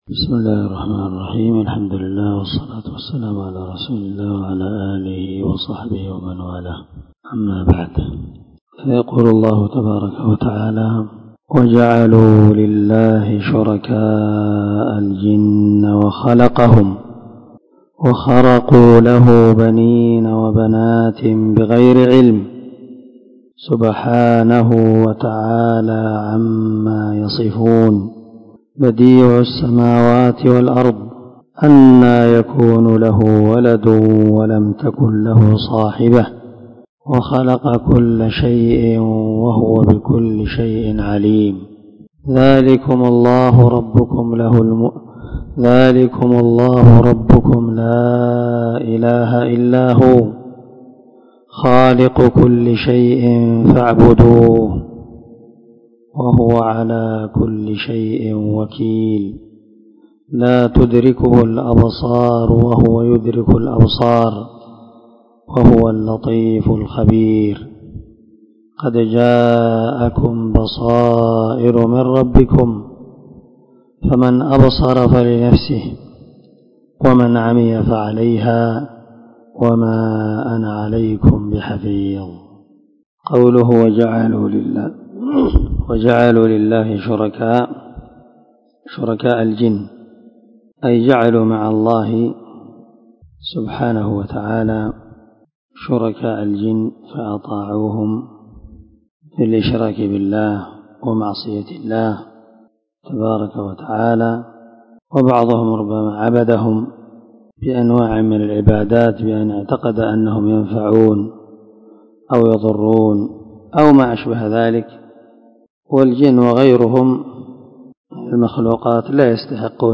425الدرس 33 تفسير آية ( 100 – 104 ) من سورة الأنعام من تفسير القران الكريم مع قراءة لتفسير السعدي
دار الحديث- المَحاوِلة- الصبيحة.